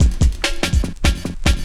16 LOOP08 -L.wav